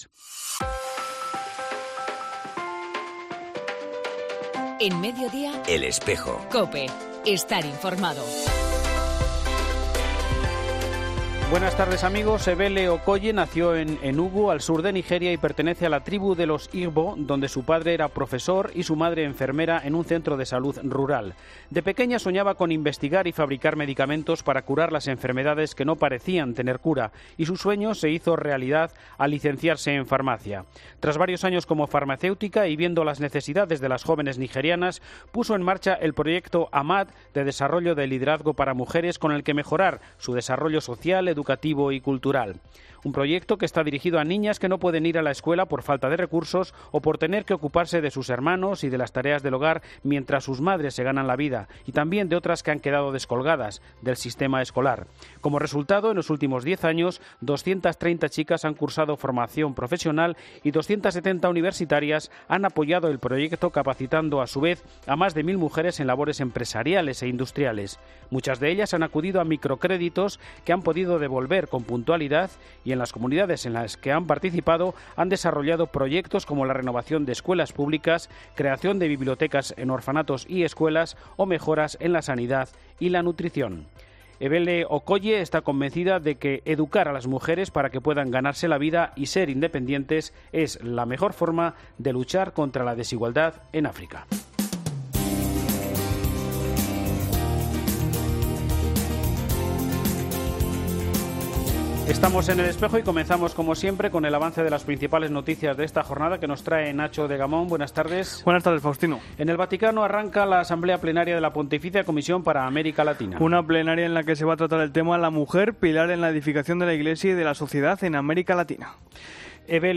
En El Espejo del 6 de marzo hemos entrevistado a Bartolomé Buigues Oller, recientemente nombrado obispo de Alajuela, en Costa Rica